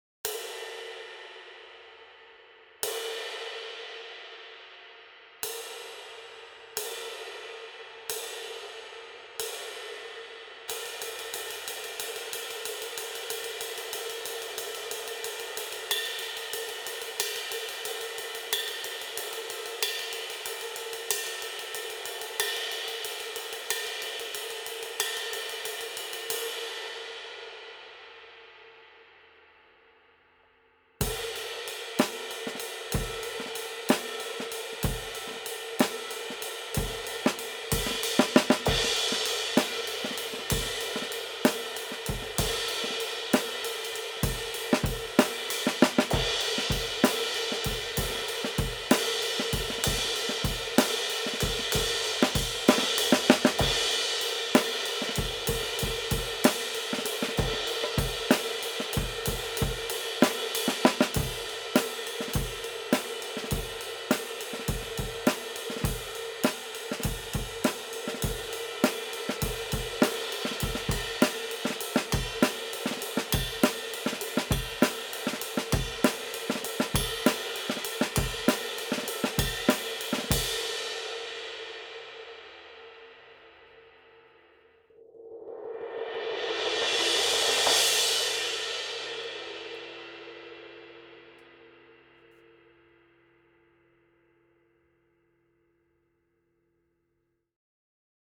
20″ ––– 1578 g - OKO Instruments
Hand-hammered from B20 bronze.